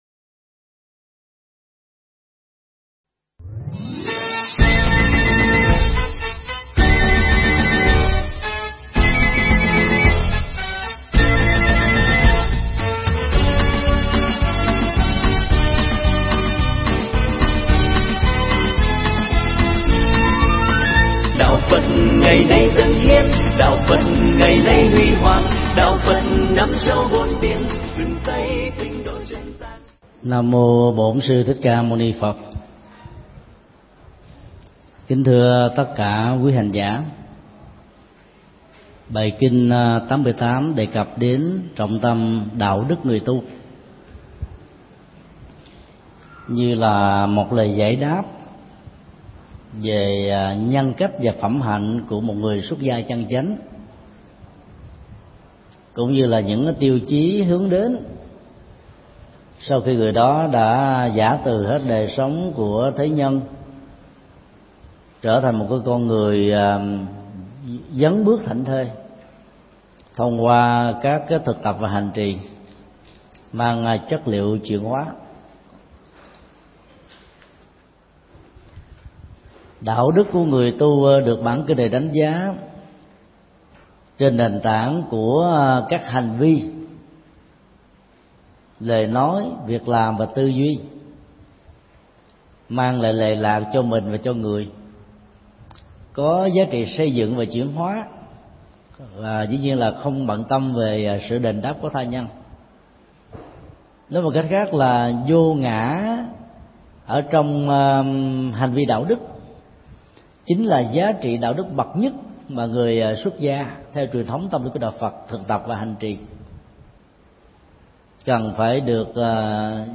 Pháp âm Hành Vi Đạo Đức – KINH BAHITIKA 88